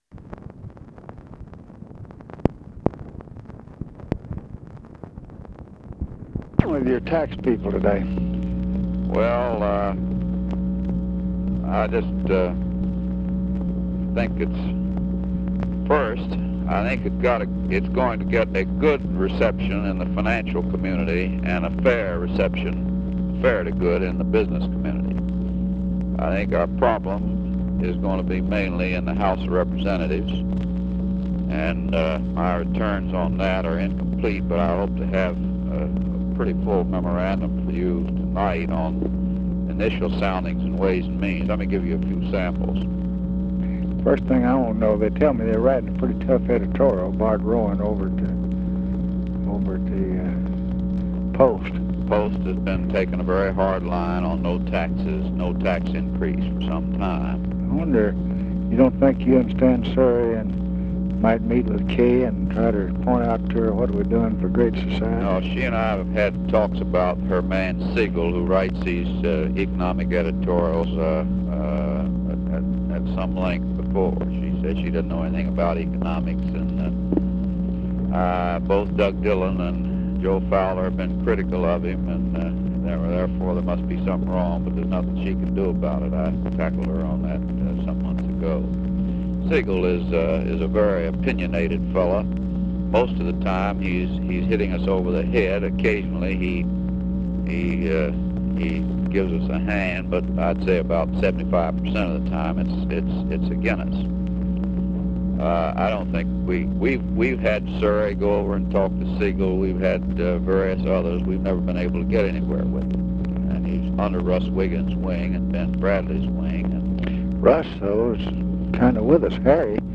Conversation with HENRY FOWLER, January 11, 1967
Secret White House Tapes